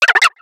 Cri de Flotajou dans Pokémon X et Y.